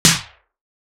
Turntables.wav